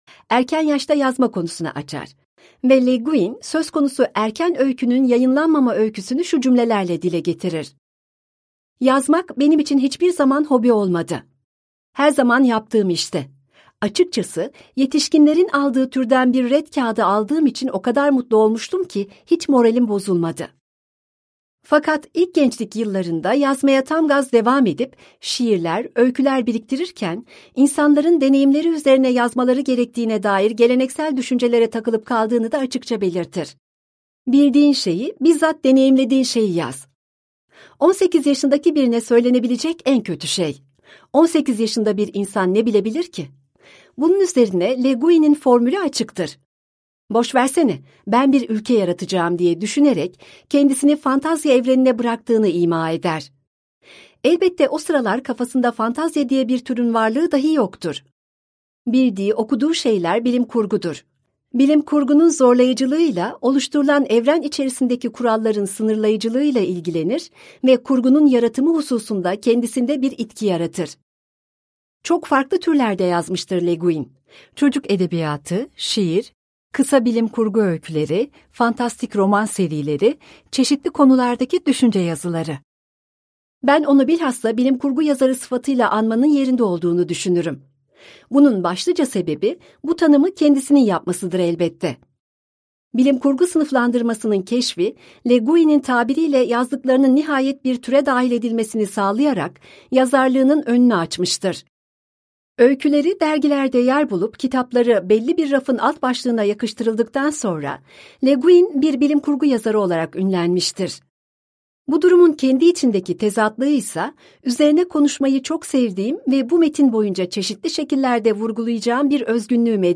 Ursula K. Le Guin - Seslenen Kitap